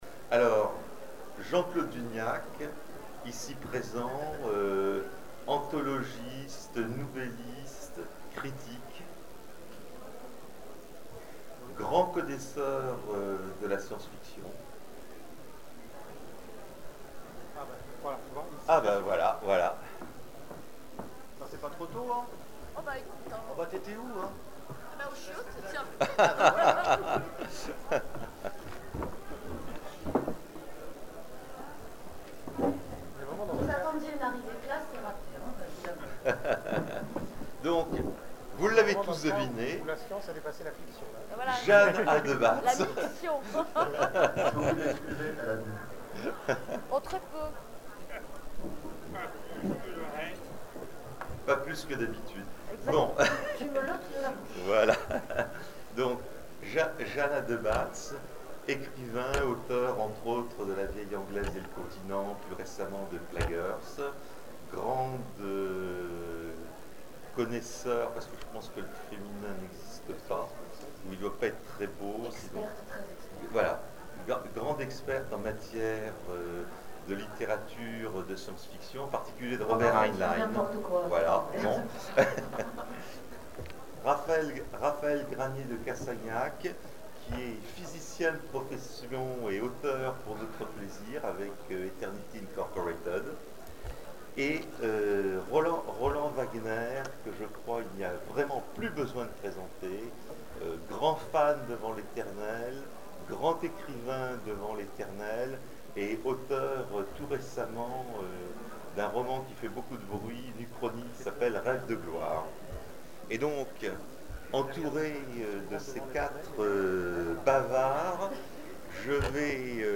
Zone Franche 2012 : Conférence Science-fiction et recherche en astrophysique : influences réciproques ?
(Attention, le son n'est pas très bon...)